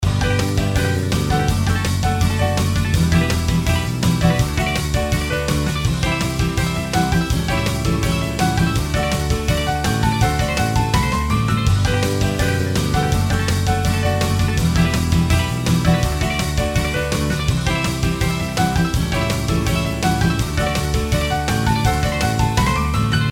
音楽ジャンル： ロック
楽曲の曲調： HARD
楽曲紹介文： 考える余裕もなく必死に追いかける様子のBGM等に